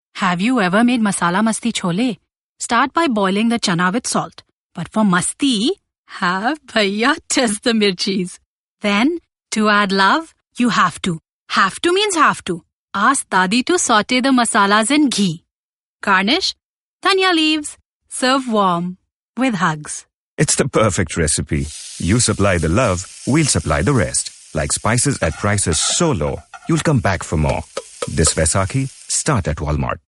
SilverMulticultural - Radio
75937 – Vaisakhi – RADIO – Savoury